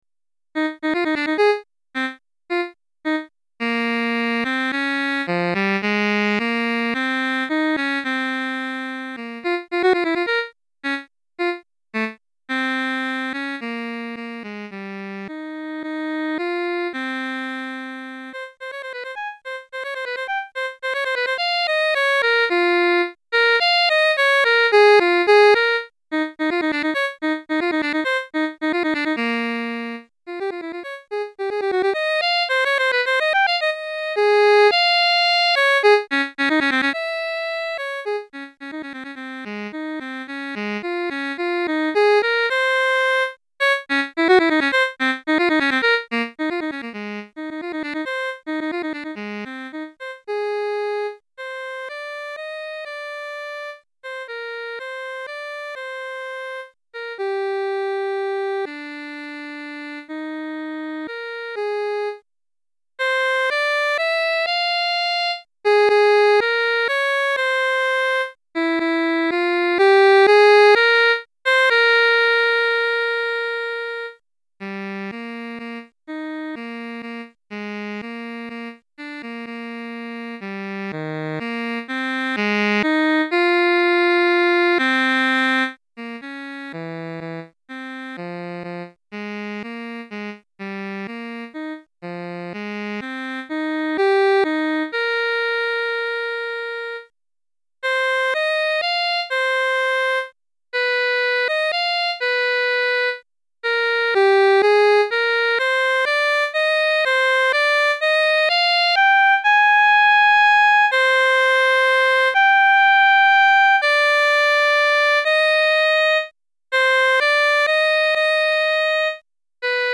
Pour saxophone solo (tous saxophones)